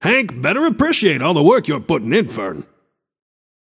Shopkeeper voice line - Hank better appreciate all the work you’re puttin‘ in, Fern.